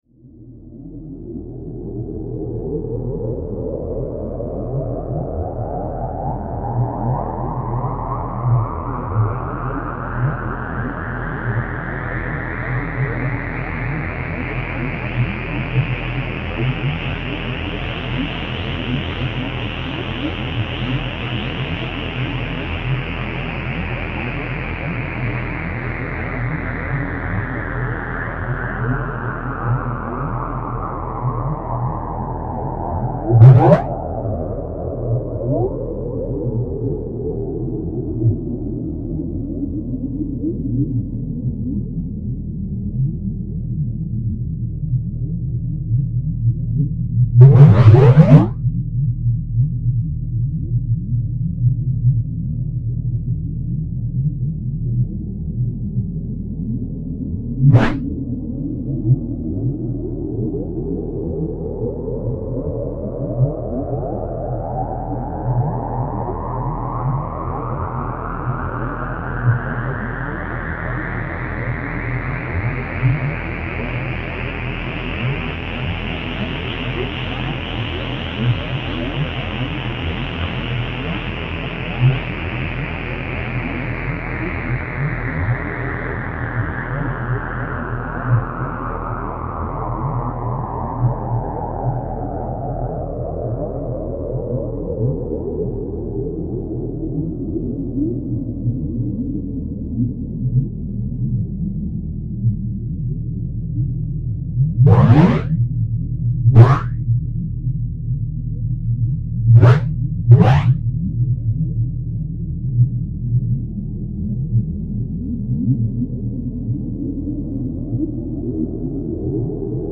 The sound of the first occurrence of a unique phenomenon which emerged when progressively overdriving a software feedback loop, with a slowly changing filter frequency sweep generator and triangle wave in the feedback loop, can be heard in Phenomes 1–8 (Audio 2).
Audio 2 (4:11). First sounds of phenomenon in Phenomes 1–8 sample.
Figure 5 shows a “bubbling” sound underneath the filtered triangle wave drone, comprised of swooping pitch objects; self-similar sound strokes occurring in a cluster of durations, amplitude and pitch trajectories.